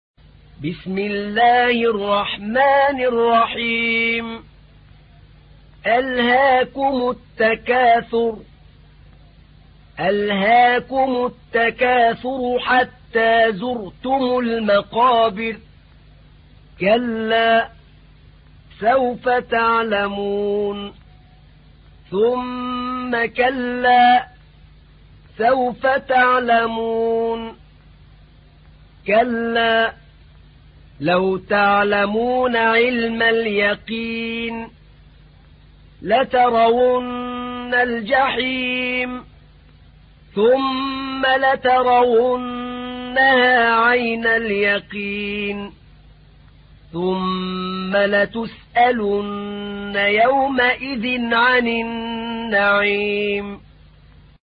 تحميل : 102. سورة التكاثر / القارئ أحمد نعينع / القرآن الكريم / موقع يا حسين